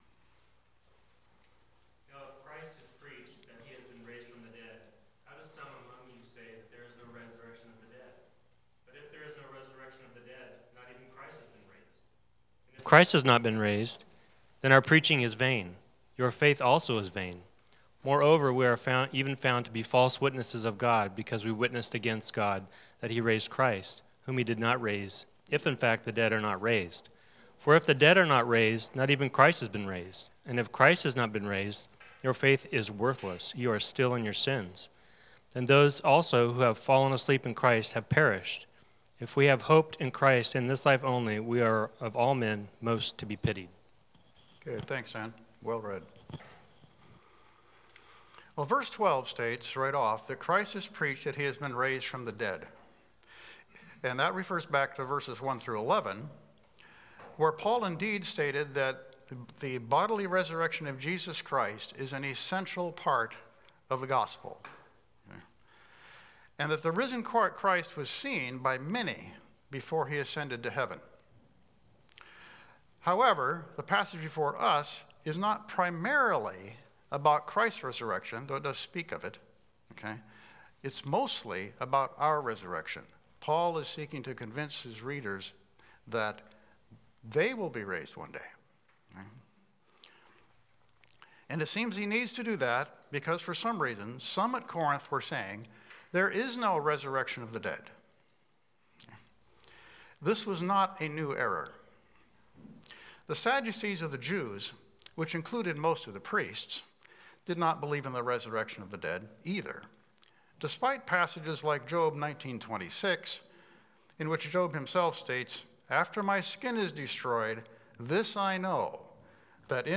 SALVATION The Sovereign Grace of our Triune God Passage: 1 Corinthians 15:12-58 Service Type: Sunday School « Childlike Faith The Way of Salvation